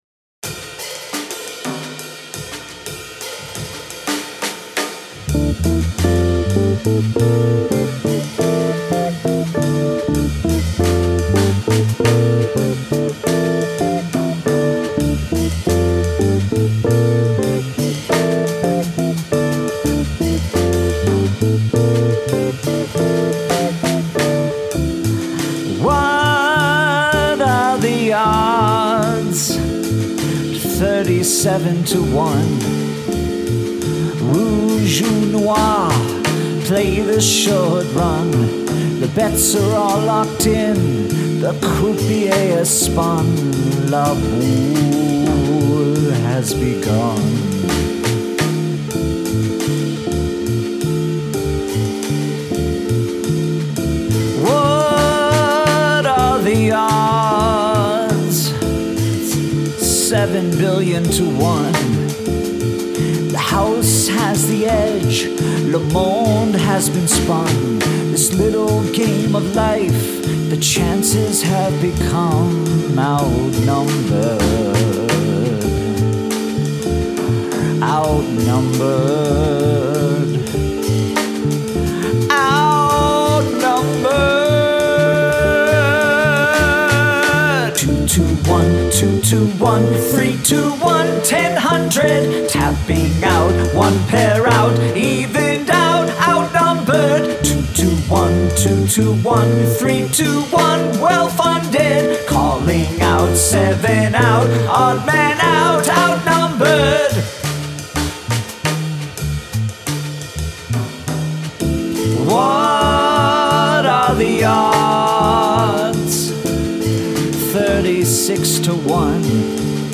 Odd Time Signature